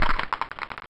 skeleton proper death sound
bonebreak.wav